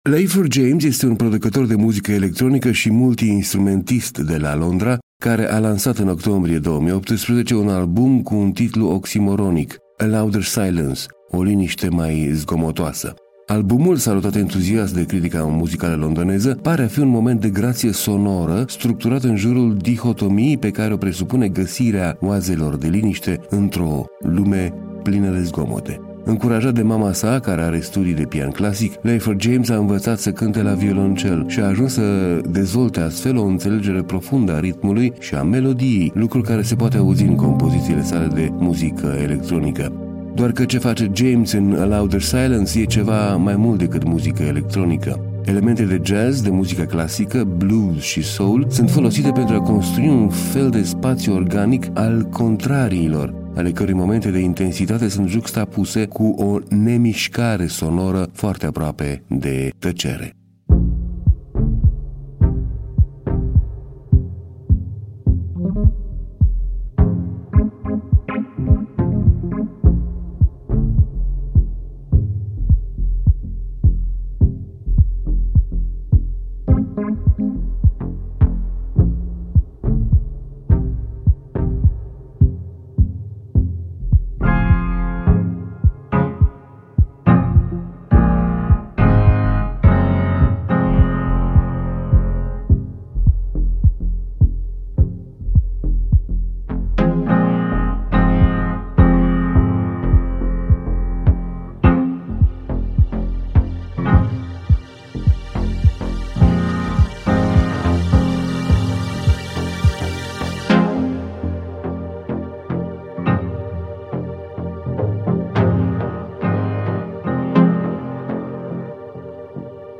Un moment de grație sonoră structurat în jurul dihotomiei pe care o presupune găsirea oazelor de liniște într-o lume plină de zgmote.